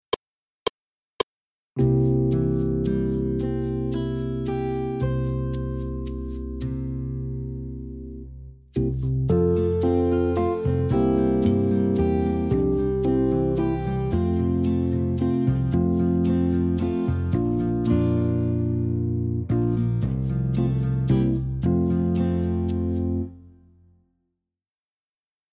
반주